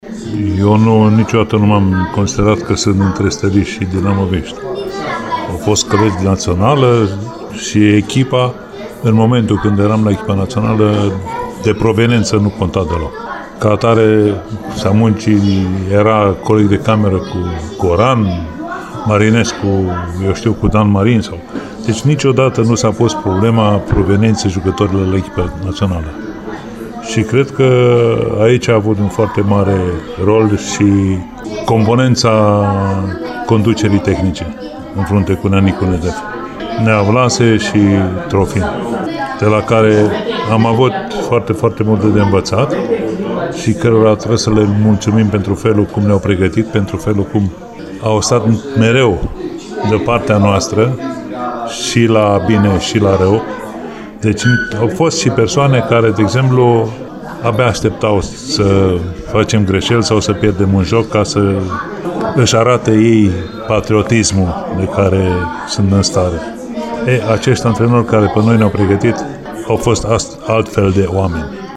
Într-una dintre înregistrările Radio Timișoara din ultimul deceniu, Roland Gunesch spune că acest lucru a contat mai puțin atunci când a îmbrăcat tricoul reprezentativei naționale: